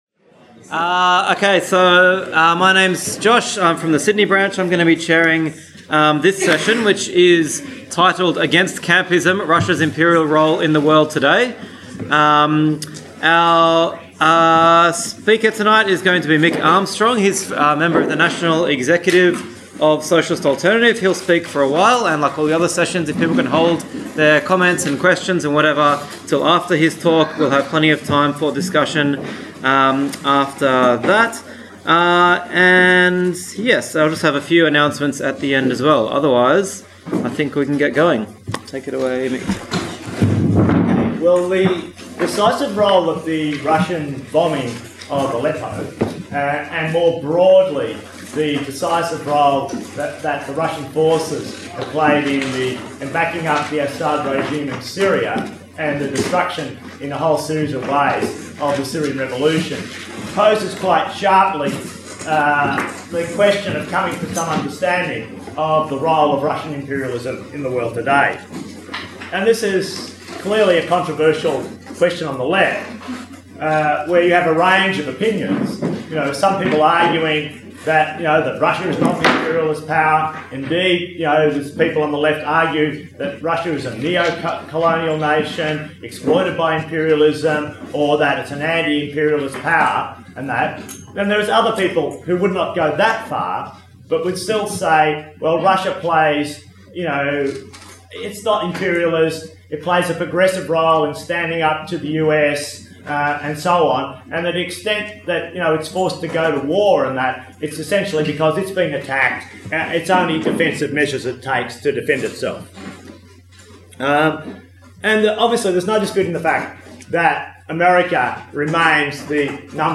Marxism 2017